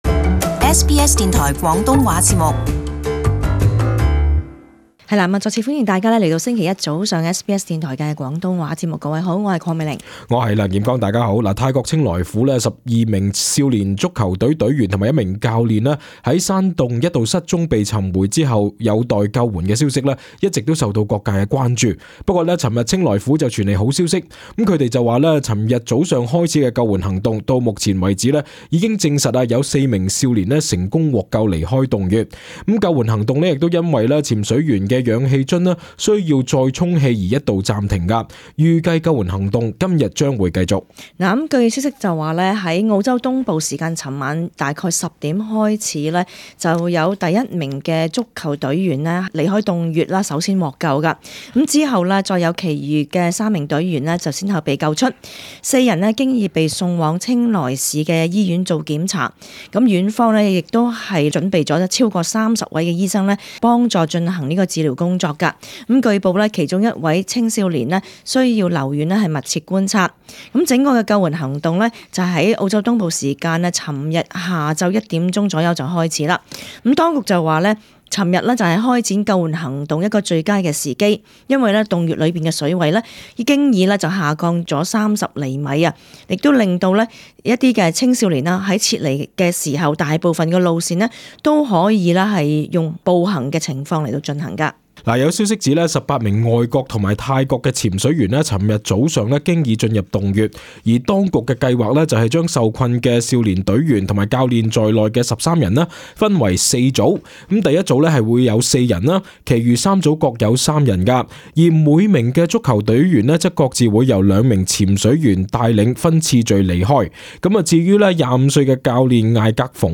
【時事報導】泰溶洞四少年獲救 行動今日繼續